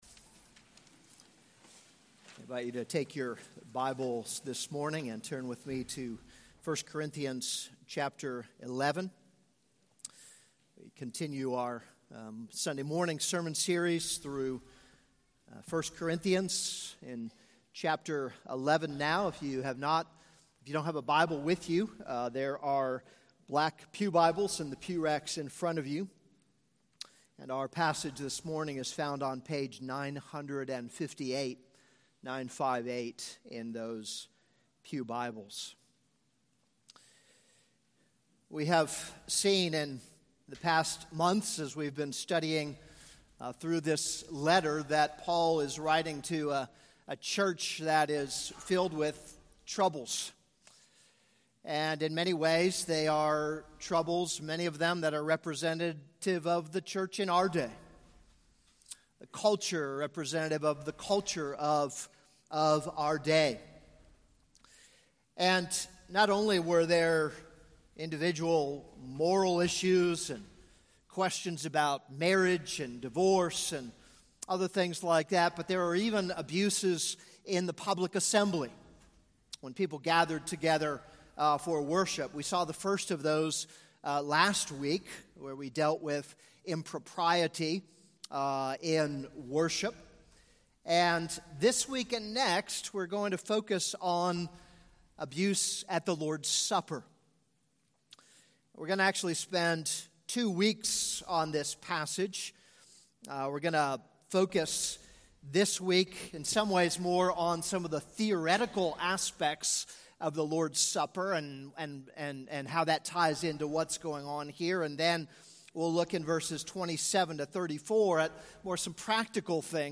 This is a sermon on 1 Corinthians 11:17-34.